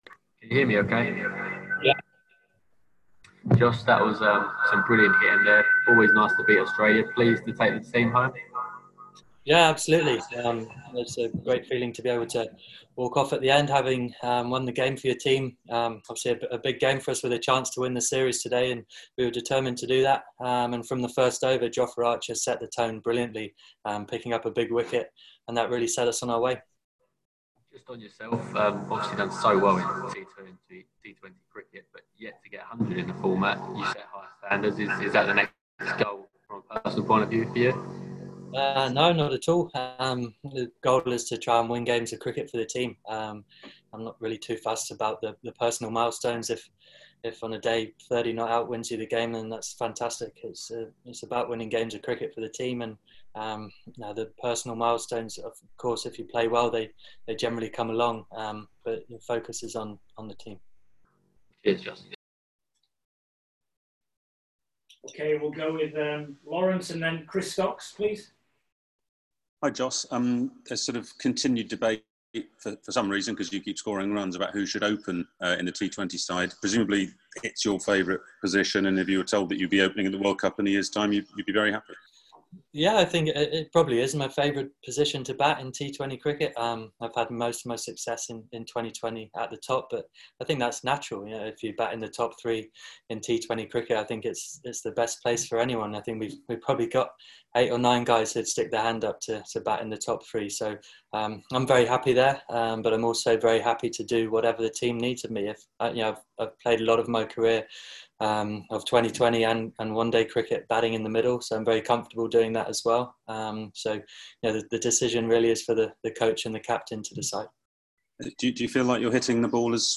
Jos Buttler speaks to the media after England win the 2nd T20I against Australia by 6 wickets